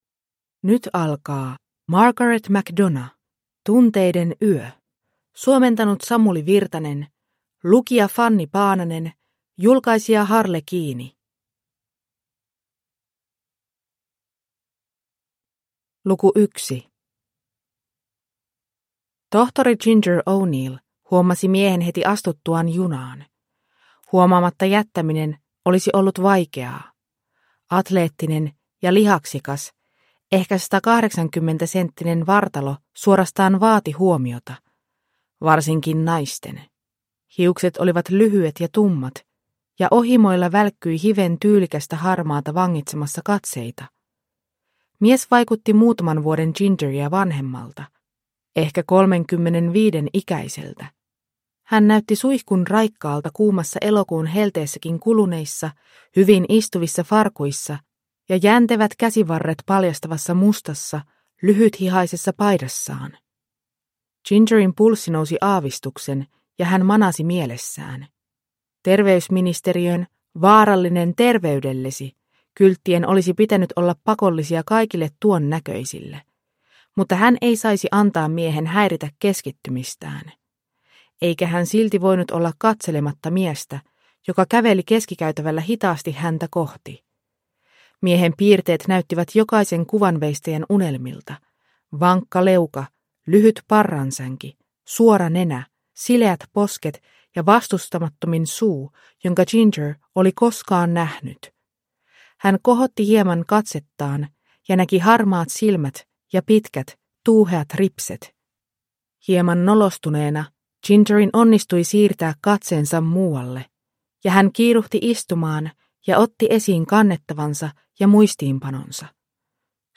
Tunteiden yö (ljudbok) av Margaret McDonagh